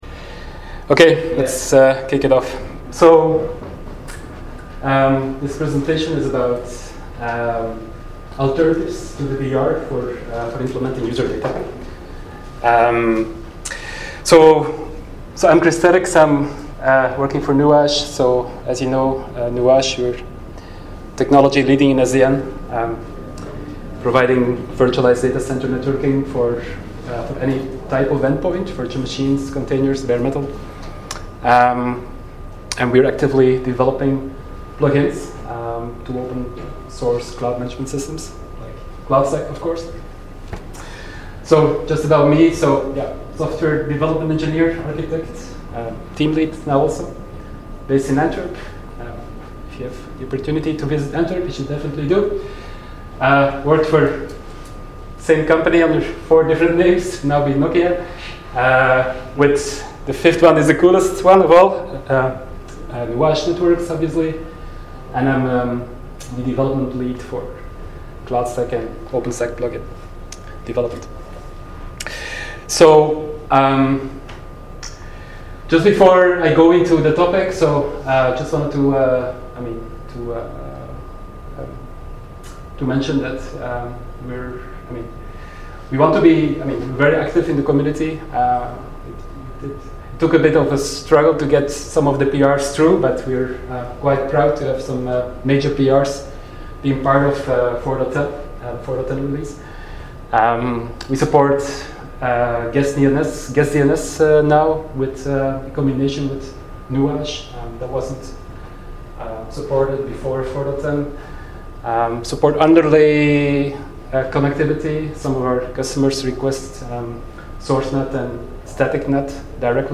ApacheCon Miami 2017